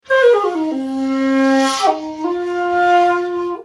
SONS ET LOOPS DE SHAKUHACHIS GRATUITS
Shakuhachi 42